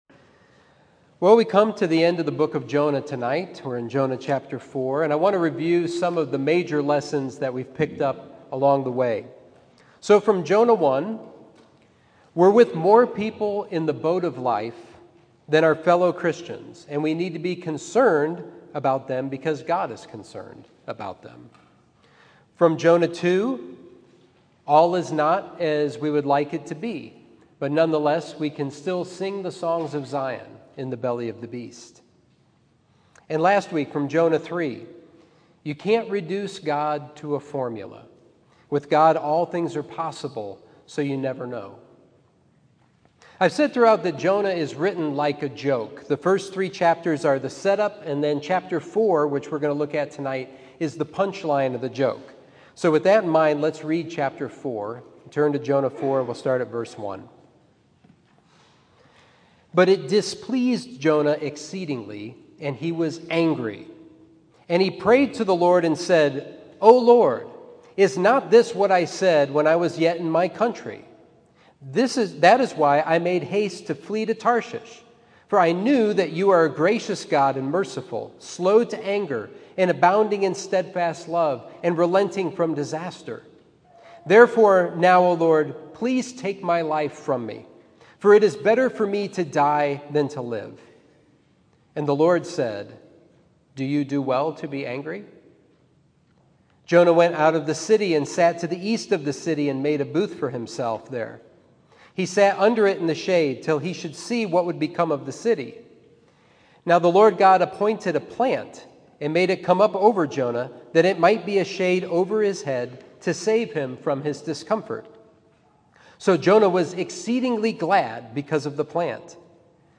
Sermon 6/6: Jonah: When the Joke’s on You